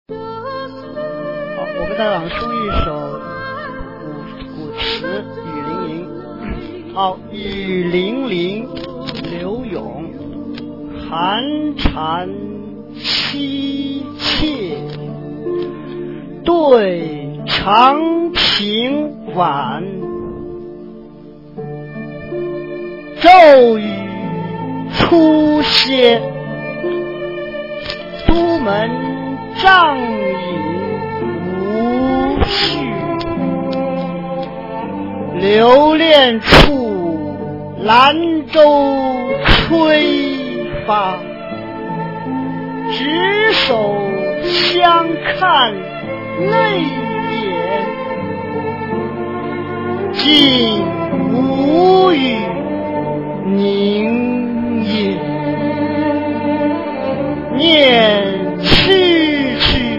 《雨霖铃》音频课文朗读